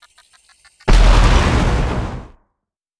Grenade Sound FX
Grenade1.wav